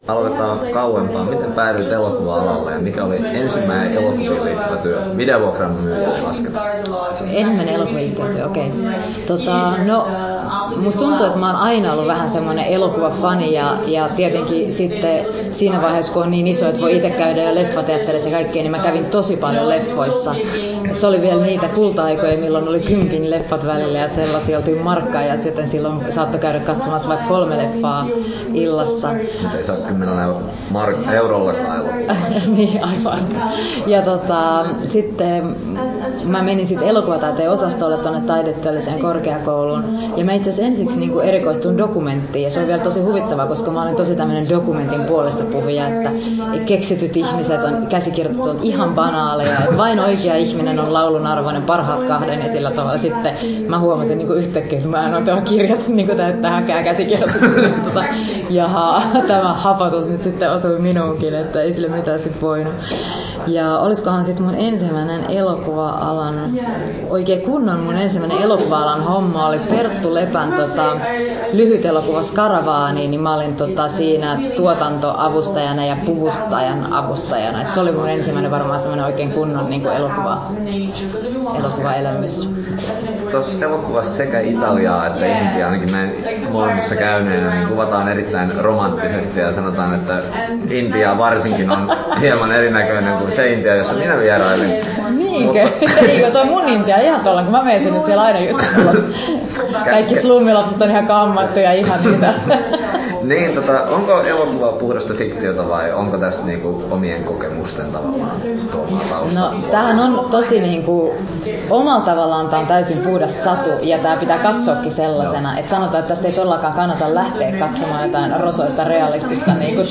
Turku Toimittaja